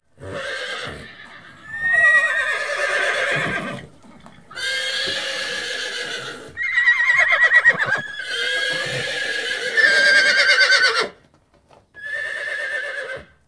horses.ogg